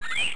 spider.wav